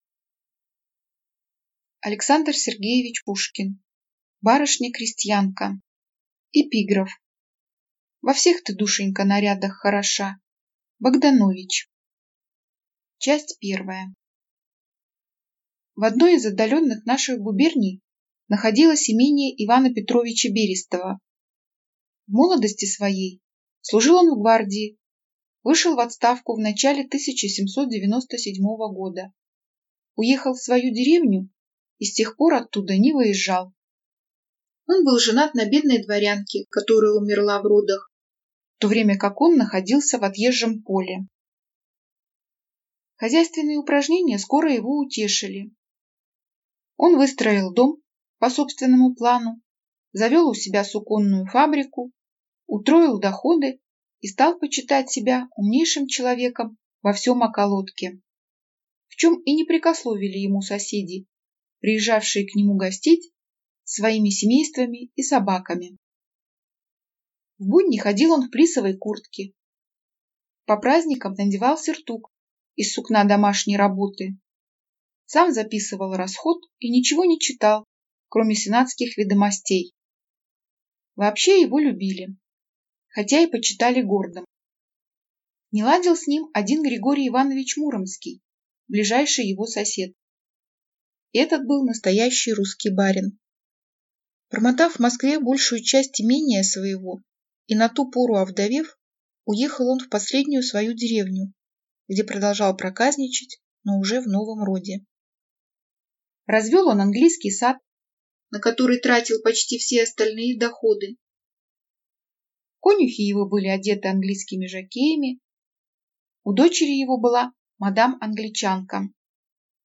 Аудиокнига Барышня-крестьянка | Библиотека аудиокниг